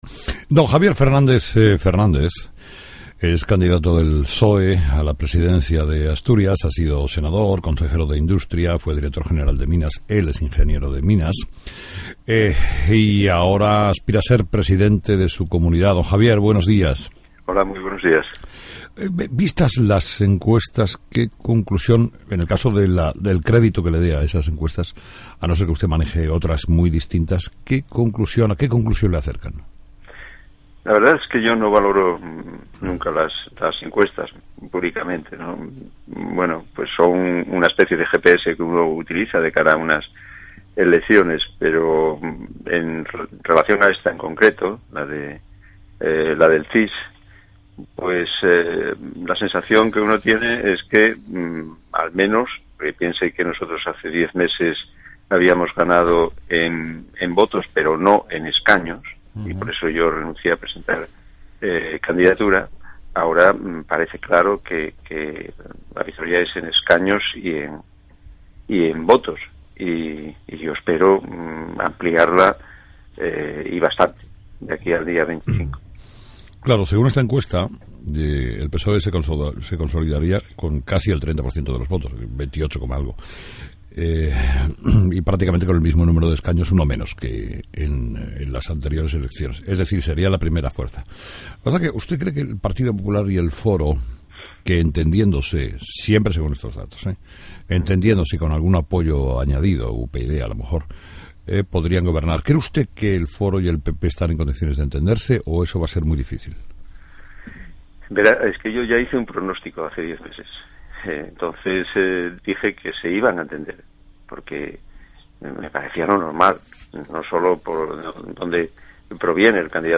Entrevista con Javier Fernández